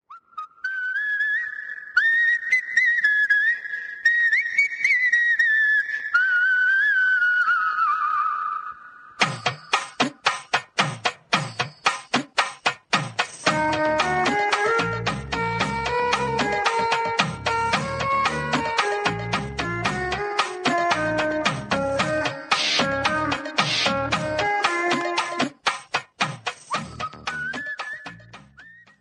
tamil ringtonelove ringtonemelody ringtoneromantic ringtone
best flute ringtone download